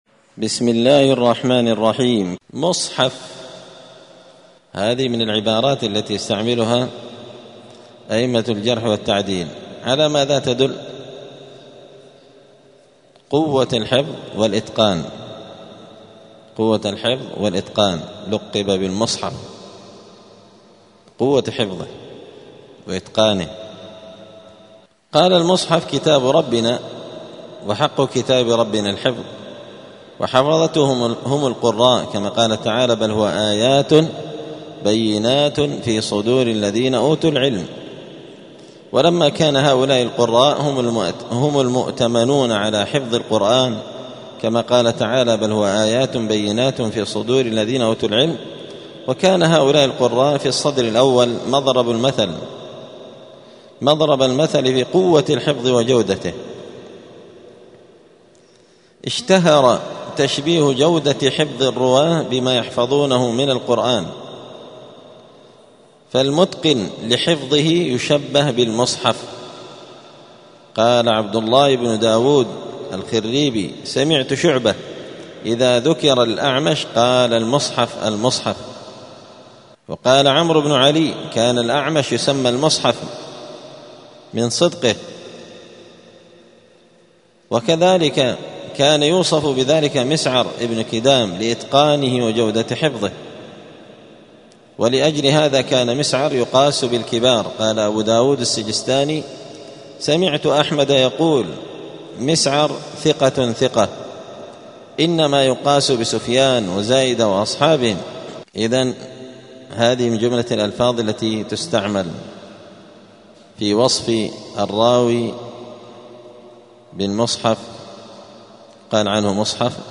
*الدرس الخامس بعد المائة (105) باب من ألفاظ الجرح والتعديل {مصحف}*